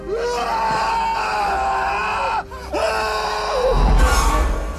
John Trent (Sam Neill) Screaming
john-trent-sam-neill-screaming.mp3